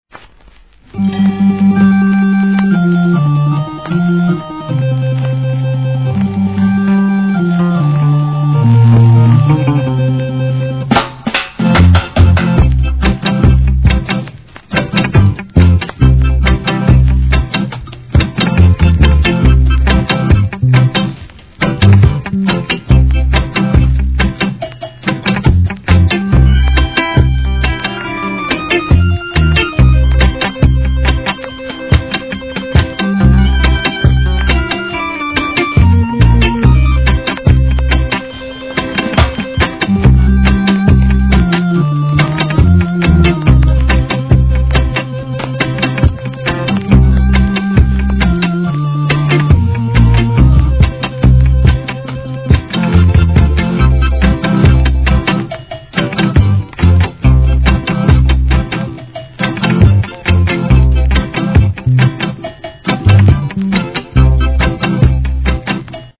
REGGAE
オルガン好INST.!!!